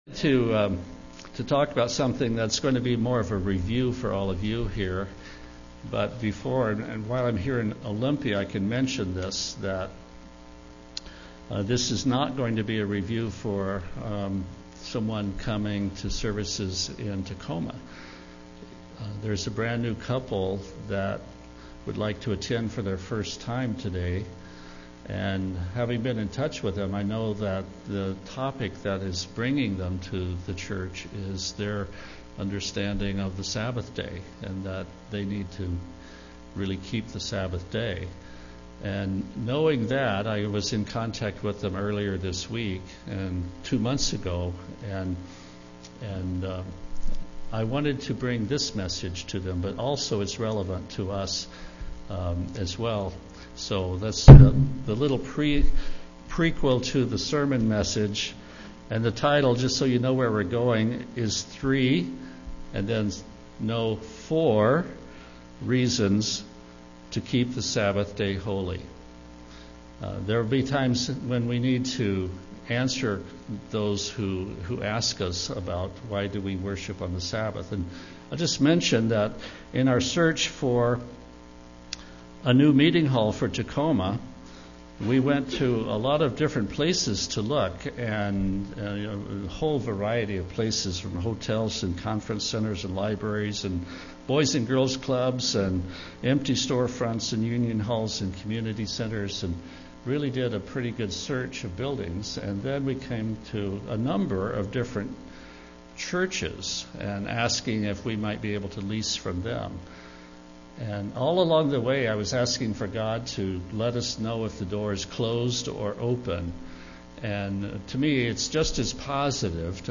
Given in Olympia, WA
UCG Sermon Studying the bible?